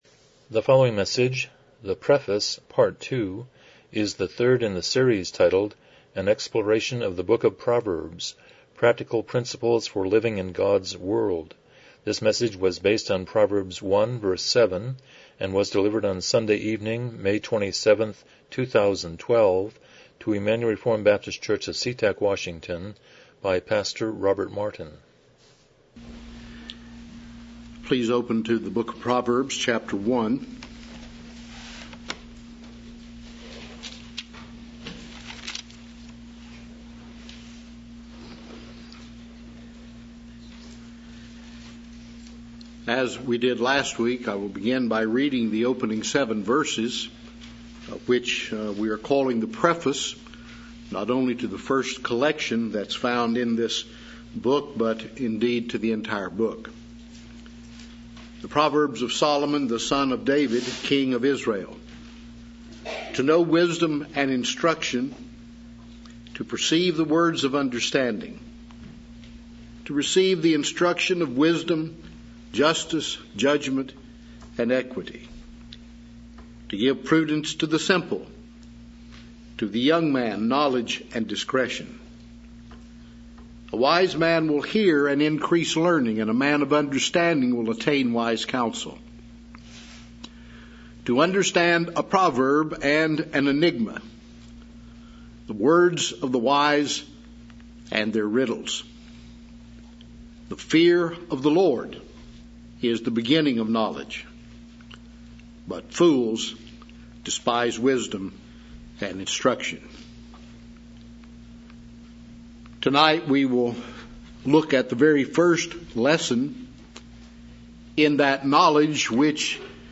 Series: Exploration of Proverbs Passage: Proverbs 1:7 Service Type: Evening Worship